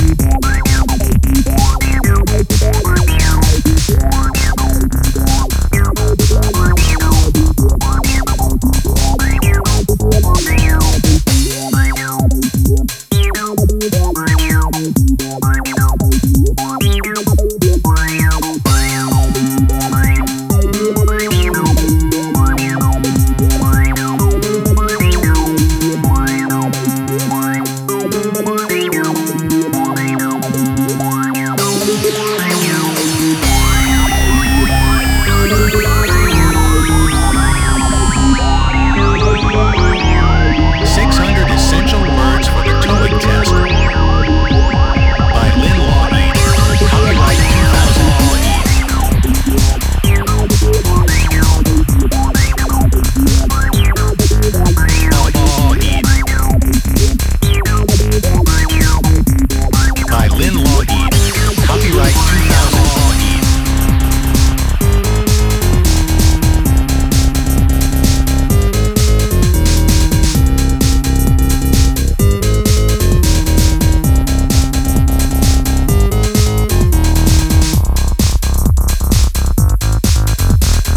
the e.p is full of timeless, heavy, dancefloor rhythms.